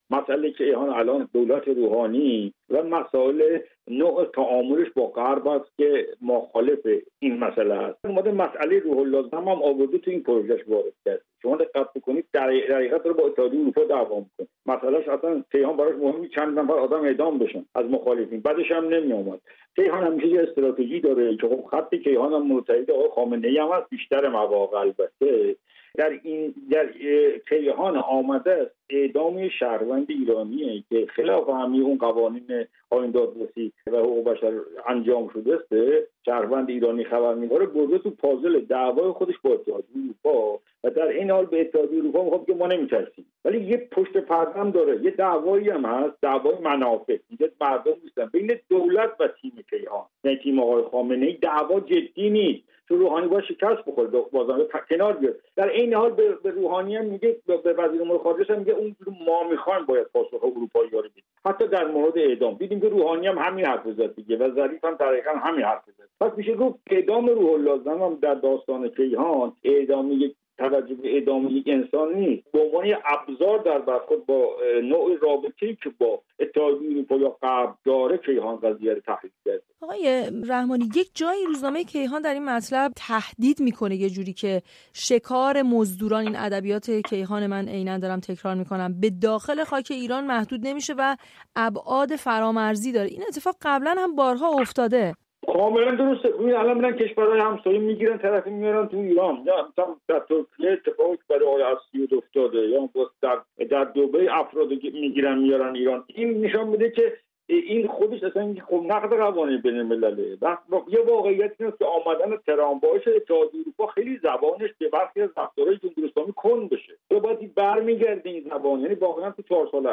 گفت و گویی